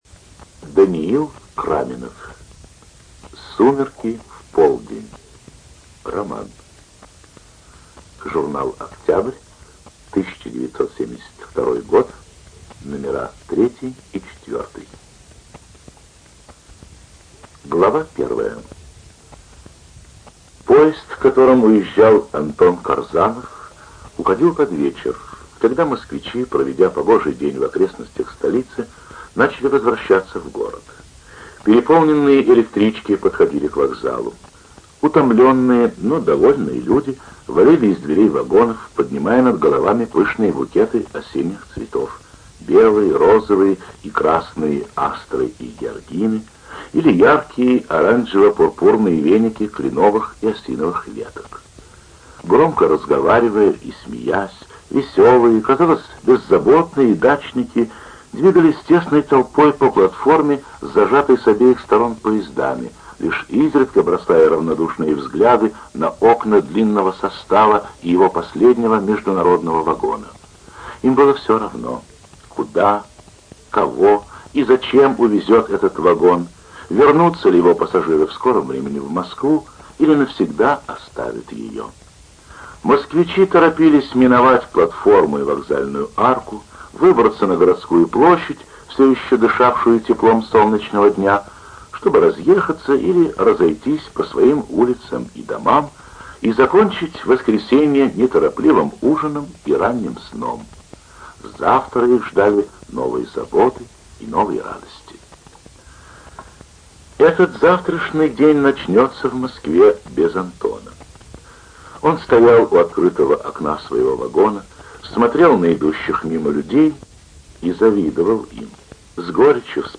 ЖанрСоветская проза
Студия звукозаписиЛогосвос